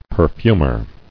[per·fum·er]